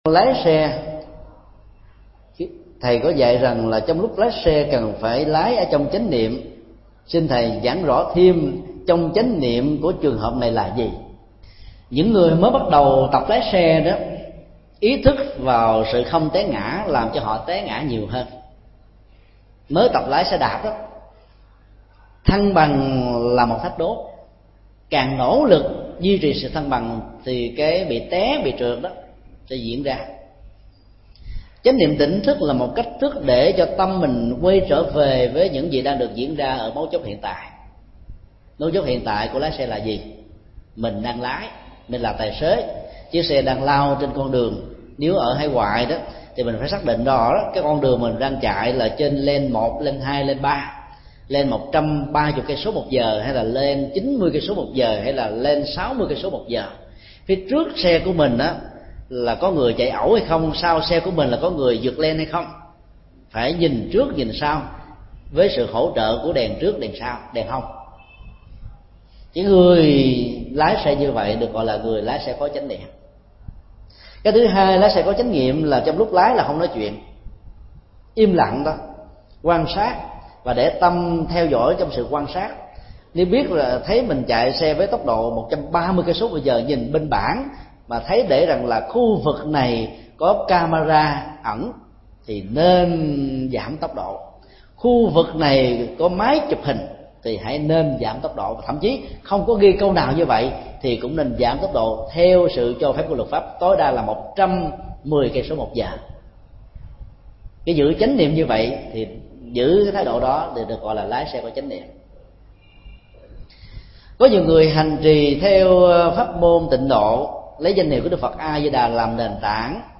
Vấn đáp: Chánh niệm khi điều khiển phương tiện giao thông